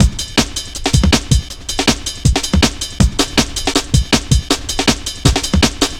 Index of /90_sSampleCDs/Zero-G - Total Drum Bass/Drumloops - 1/track 04 (160bpm)